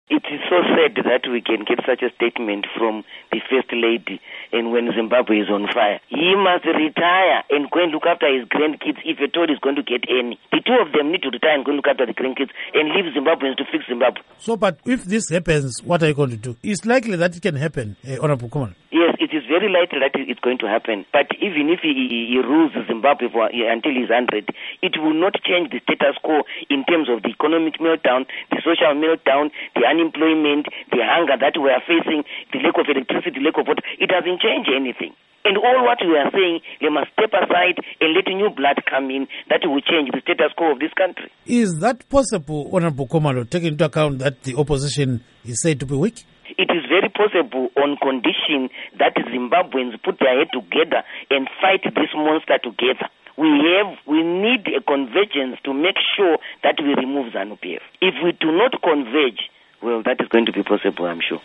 Interview With Thabitha Khumalo on Grace Mugabe Wheelchair Remarks